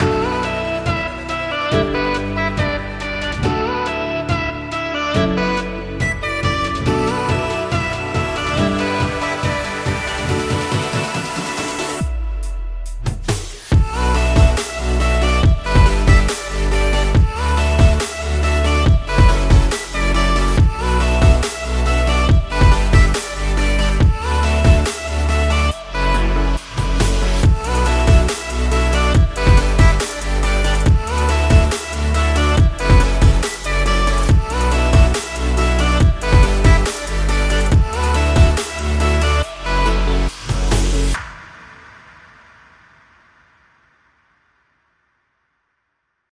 您将获得专业的人声挂钩，人声喊叫声，大声码器，2个工具包等等！
20个声乐挂钩（干+湿）
10个声码器乐曲循环
10个反向声乐